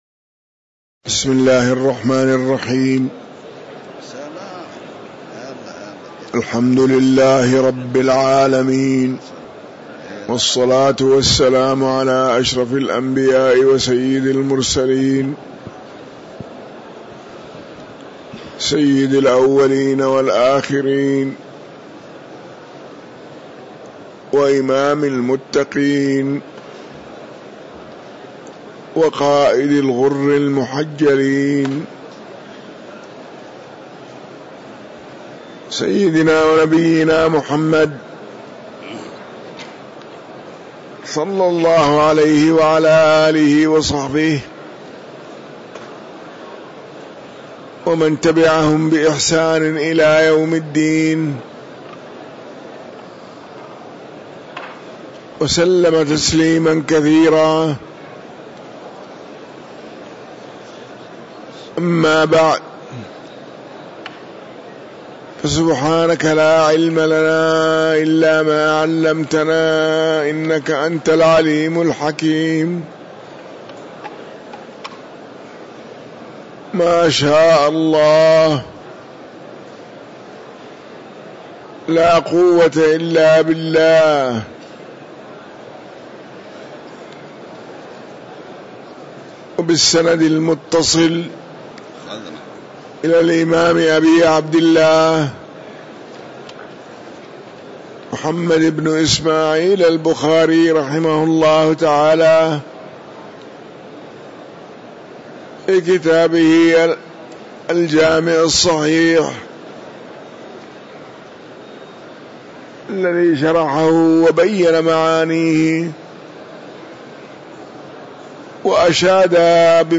تاريخ النشر ١٤ رجب ١٤٤٤ هـ المكان: المسجد النبوي الشيخ